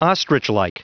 Prononciation audio / Fichier audio de OSTRICHLIKE en anglais
Prononciation du mot ostrichlike en anglais (fichier audio)